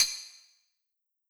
Cardi Tam Hit 1.wav